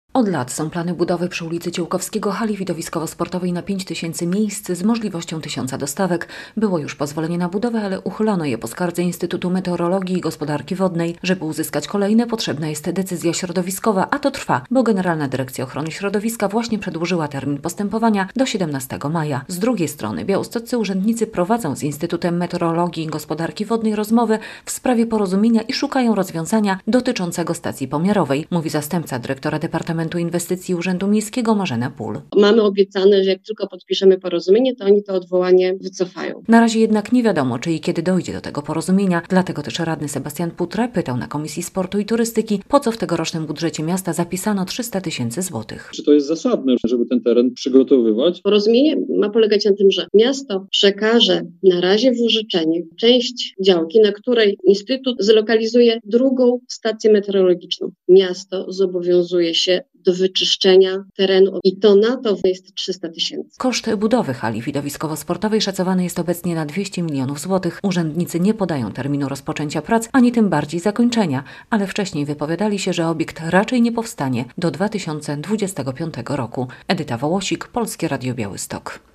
Brak decyzji środowiskowej opóźnia budowę hali widowiskowo-sportowej w Białymstoku - relacja
O tym dyskutowali radni z Komisji Sportu i Turystyki.